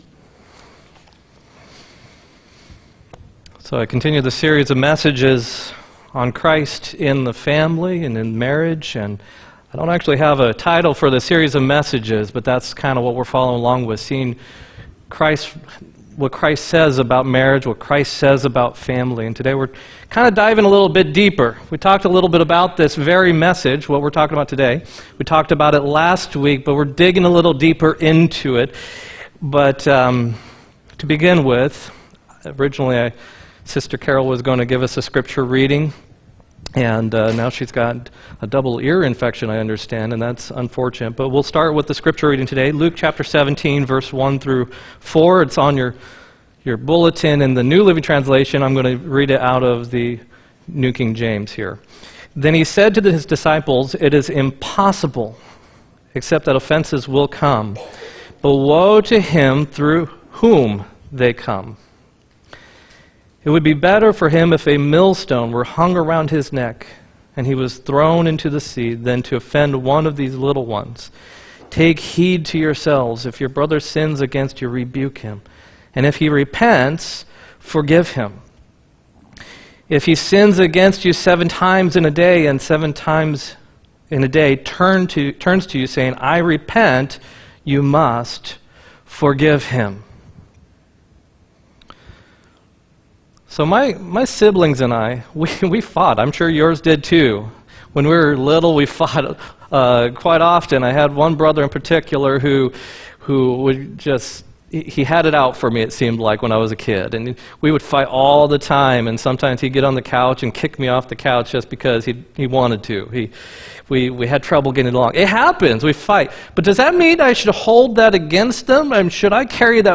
5-26-18 sermon